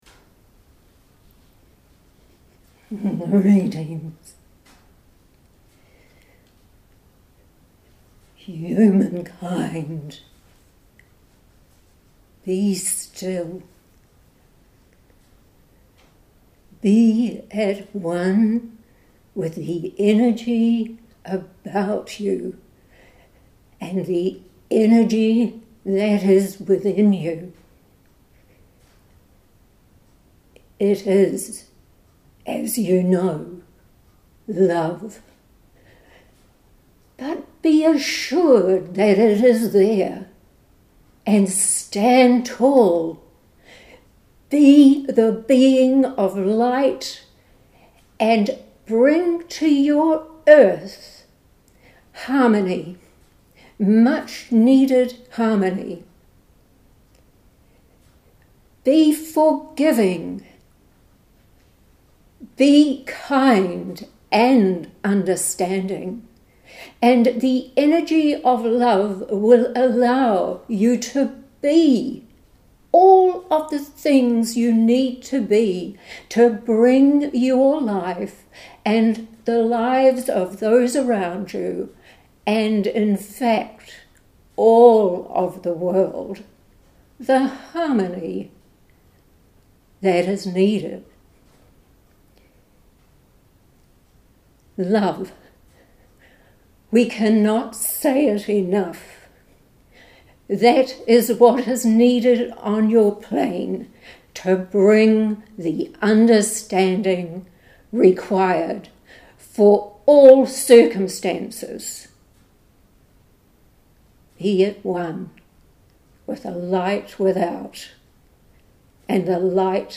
during a meeting of our meditation group.